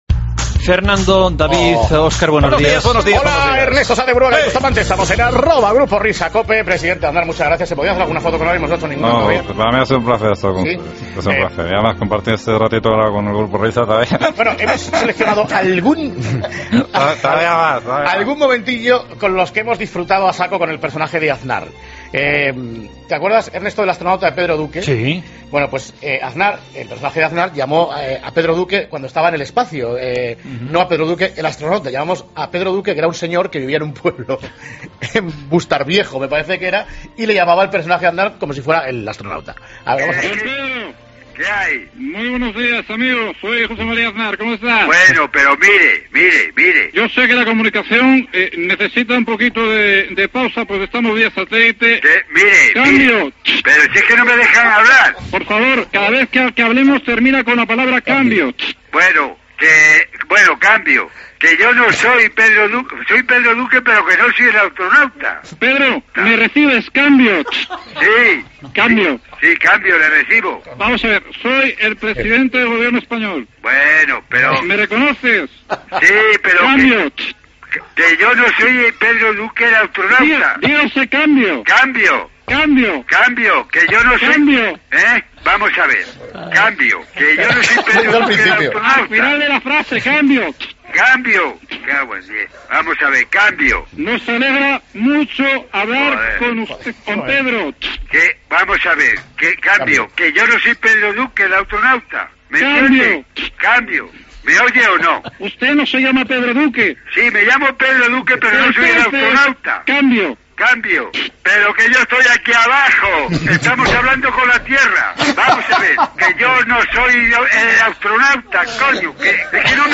El grupo Risa protagonizó esta divertida recreación de la llamada de teléfono